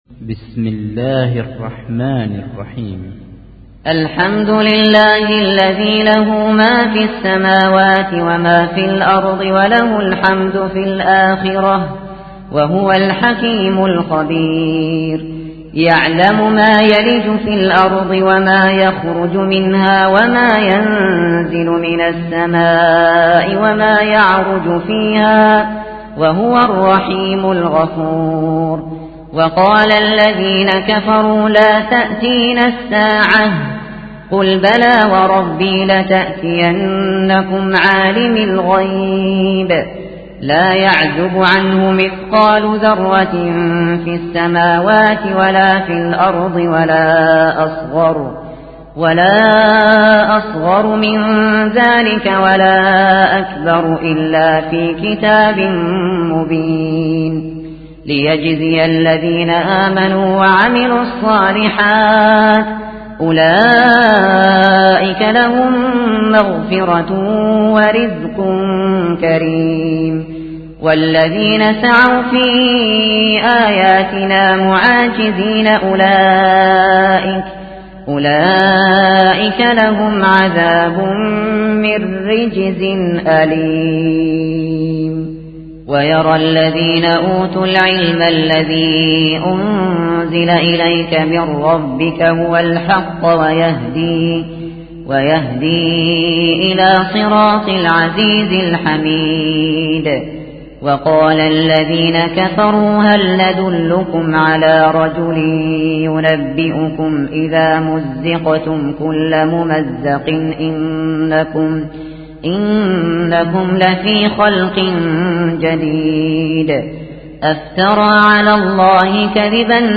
ملف صوتی سورة سباء بصوت الاستاذ الشاطري